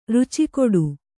♪ ruci koḍu